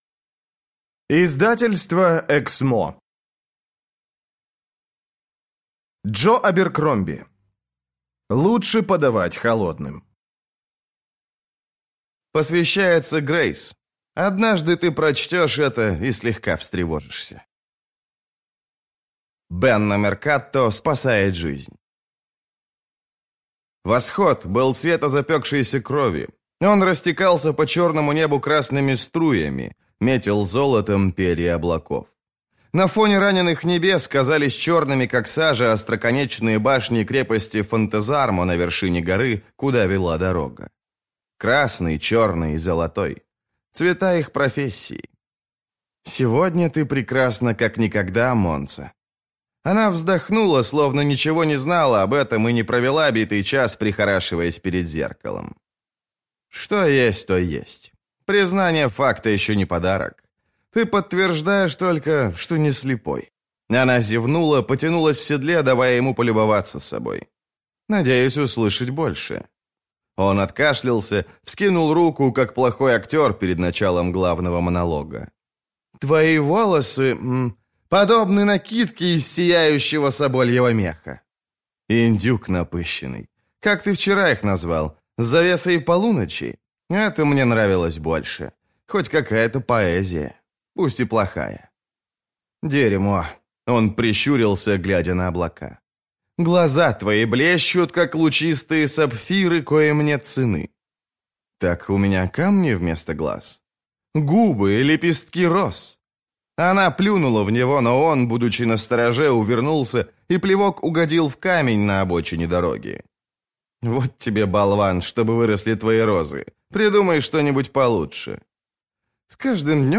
Аудиокнига Лучше подавать холодным | Библиотека аудиокниг